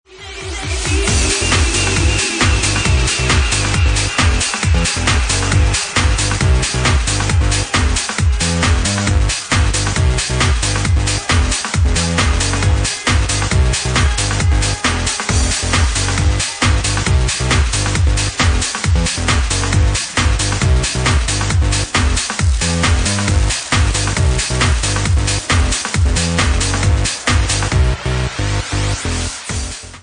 Bassline House at 136 bpm